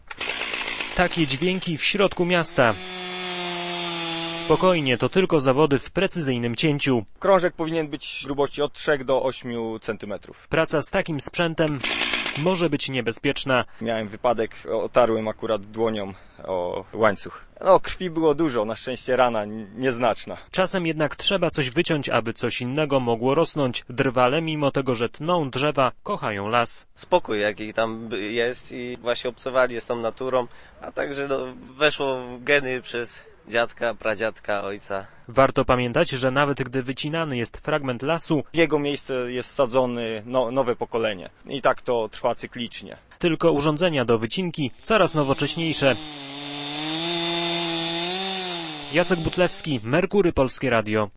Biorą oni udział w zorganizowanych w Poznaniu zawodach. Jedną z konkurencji jest precyzyjna obróbka kłody.